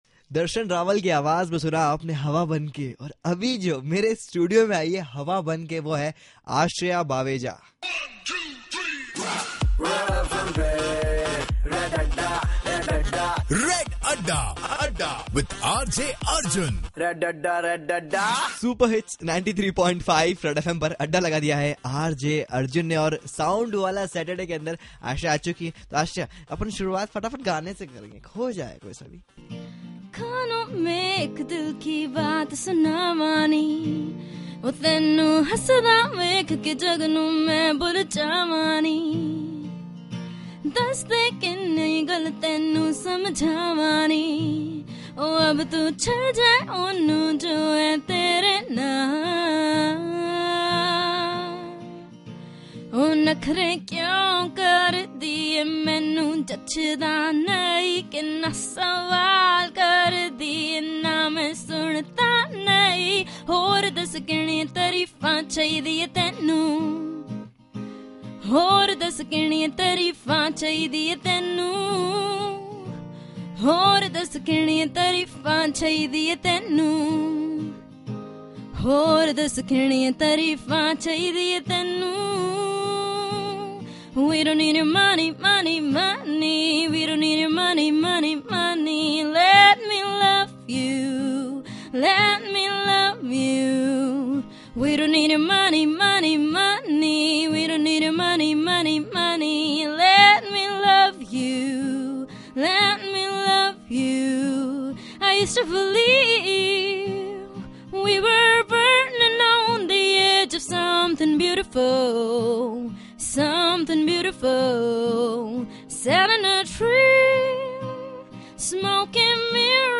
Chit Chat with Singers